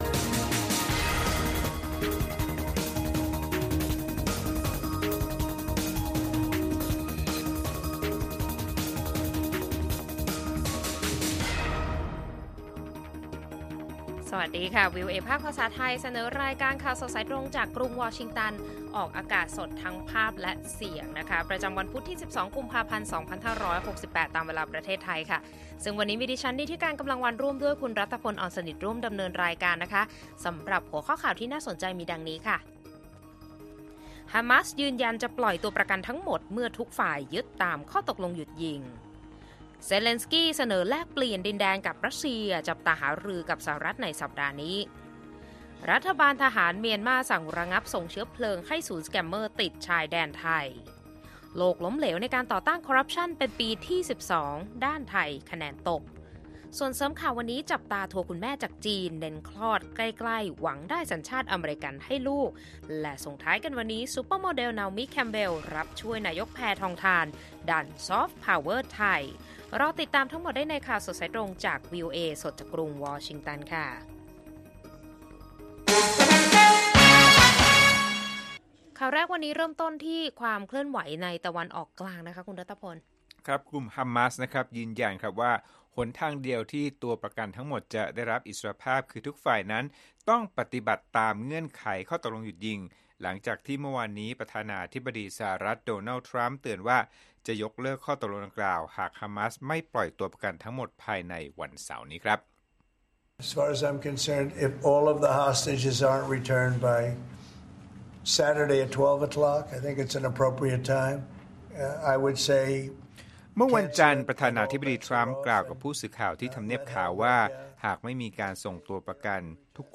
ข่าวสดสายตรงจากวีโอเอ ภาคภาษาไทย พุธ ที่ 12 กุมภาพันธ์ 2568